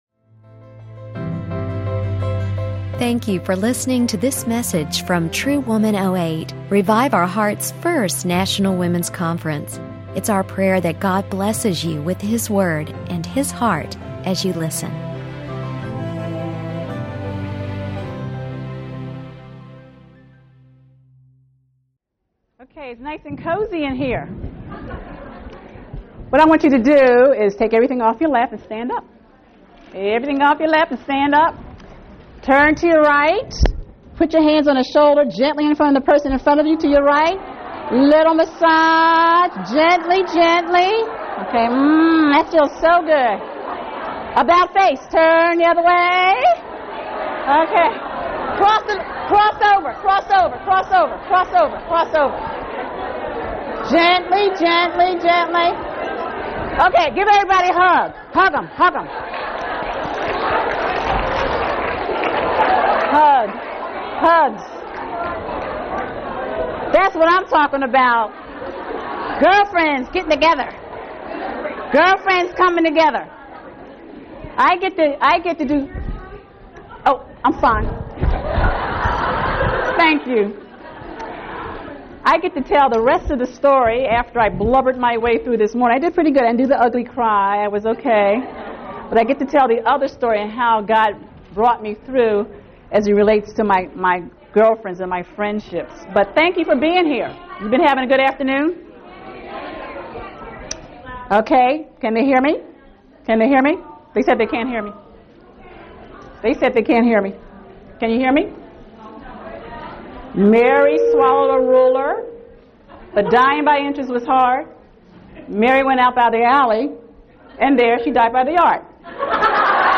Portrait of Friendship | True Woman '08 | Events | Revive Our Hearts